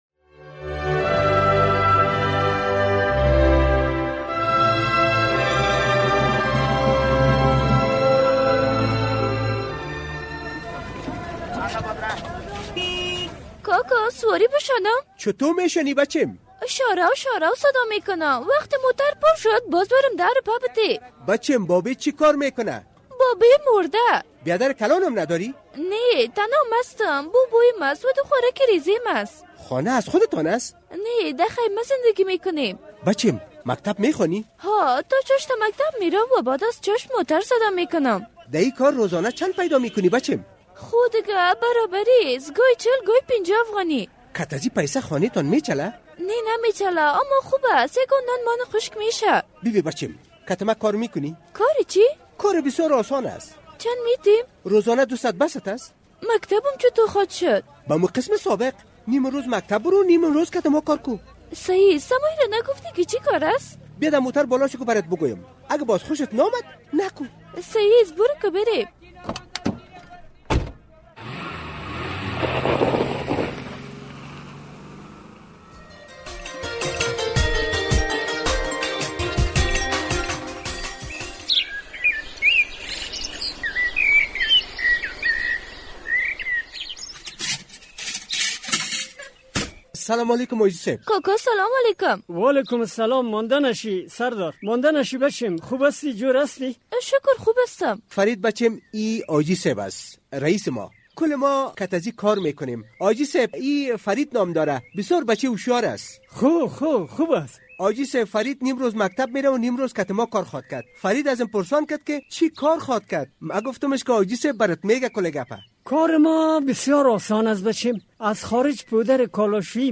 درامه کاروان زهر